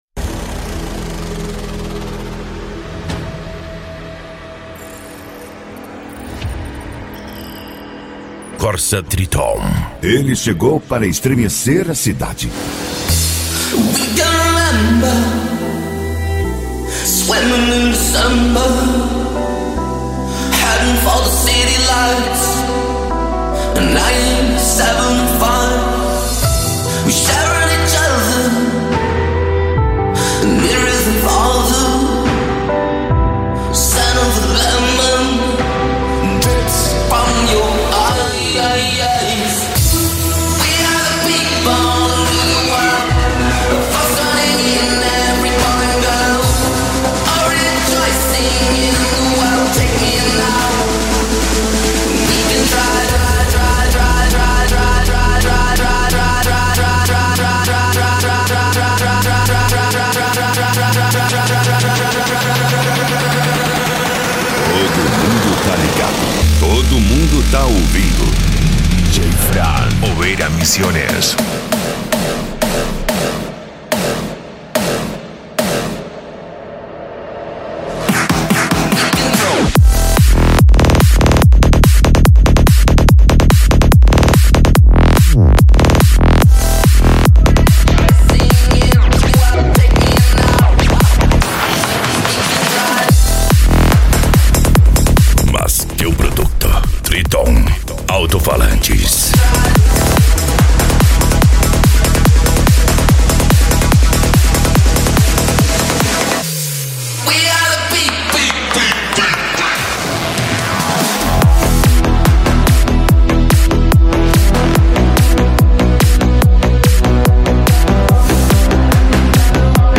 Deep House
Electro House
Psy Trance
Remix